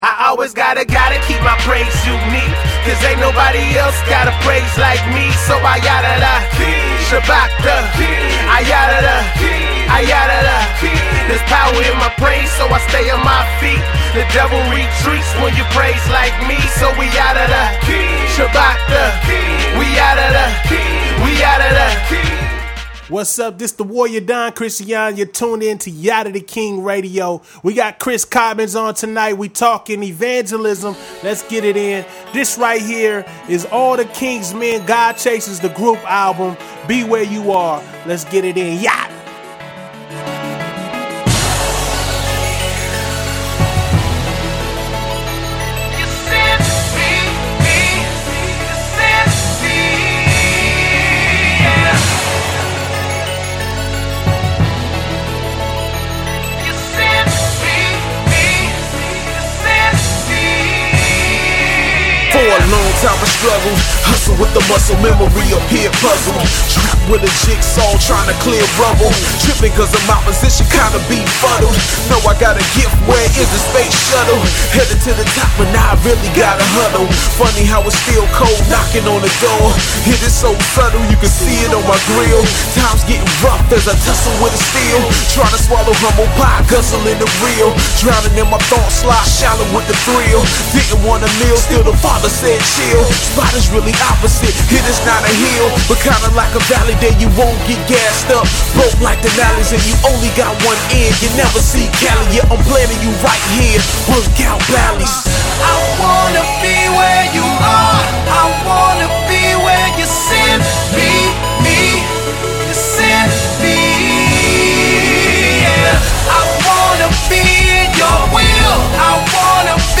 AFTERPARTY INTERVIEW